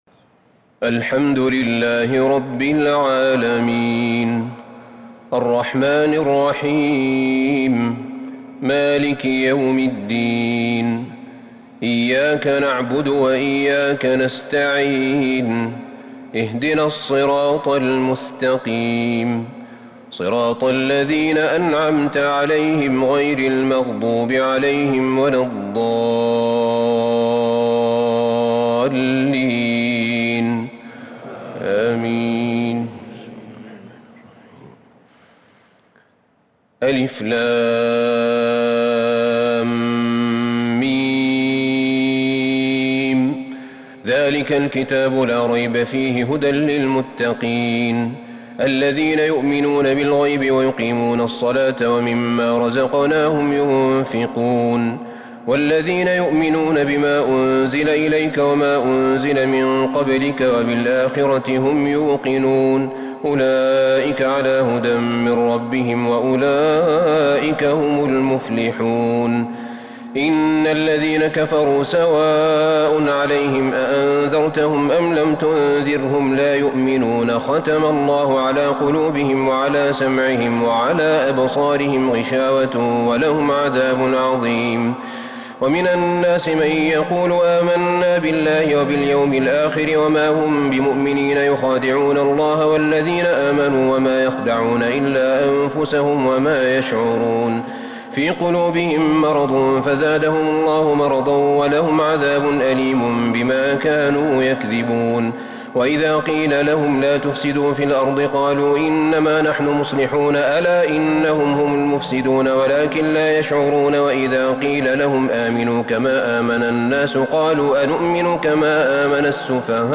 تراويح ليلة 1 رمضان 1442 من سورة البقرة (1-66) Taraweeh 1st night Ramadan 1442H > تراويح الحرم النبوي عام 1442 🕌 > التراويح - تلاوات الحرمين